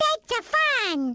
One of Baby Mario's voice clips from the Awards Ceremony in Mario Kart: Double Dash!!